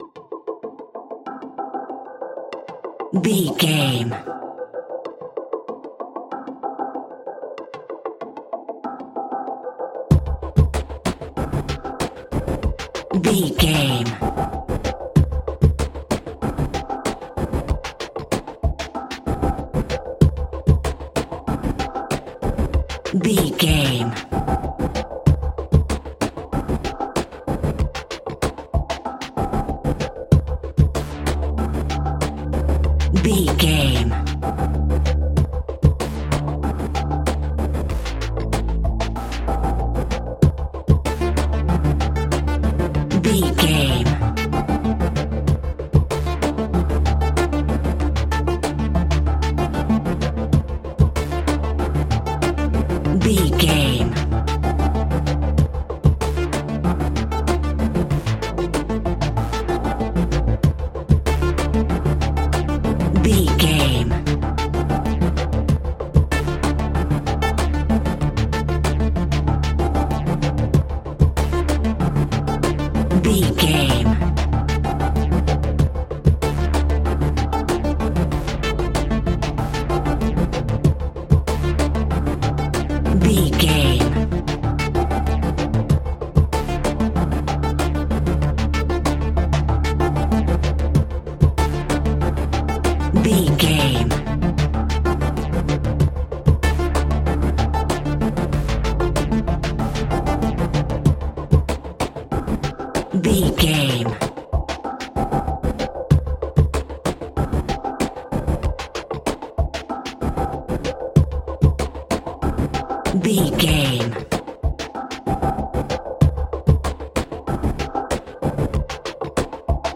Aeolian/Minor
piano
synthesiser